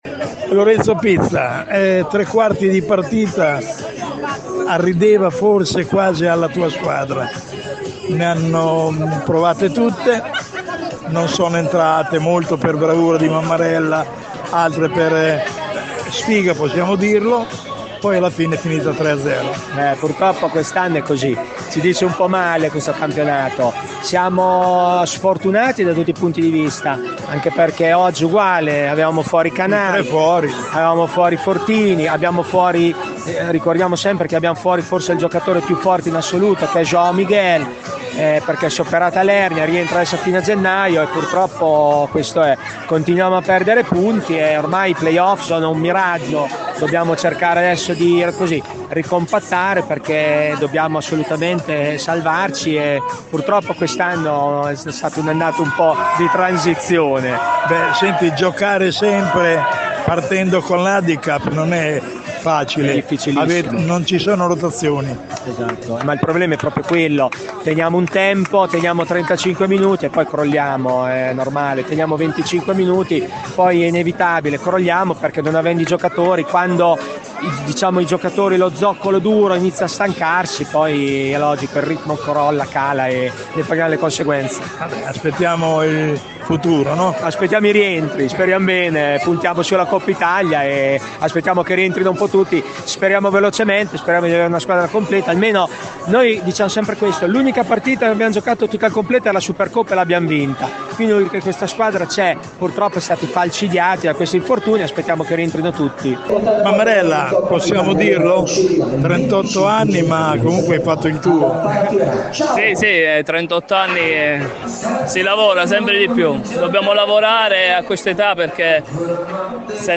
Le interviste post partita